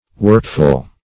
Workful \Work"ful\, a. Full of work; diligent.